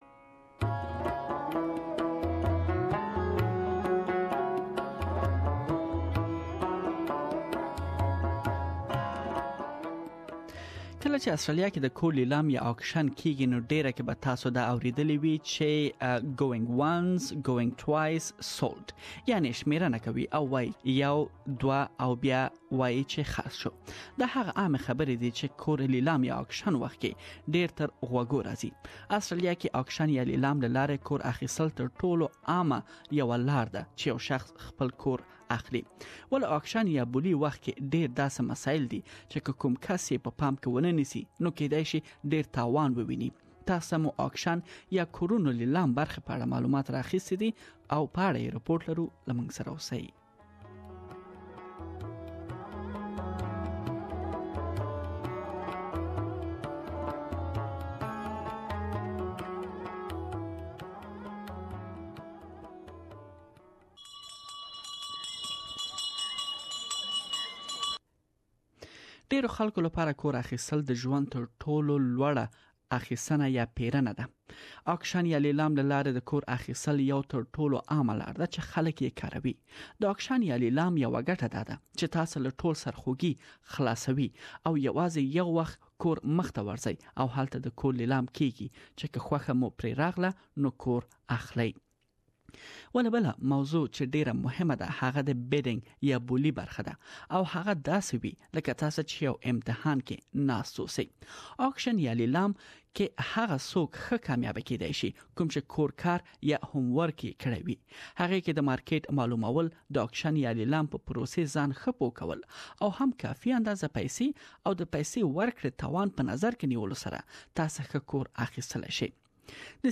Buying at auction is one of the most popular ways to buy a house, but there are many traps for those who do not know the rules and may end up making a costly mistake. This report has a few tips for buying successfully at auction.